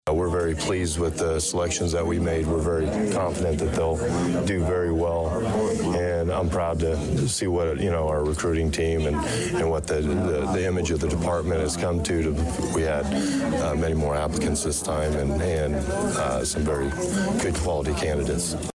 Danville Police Chief Christopher Yates says the five probationary officers were chosen from an initial pool of 27 interested parties.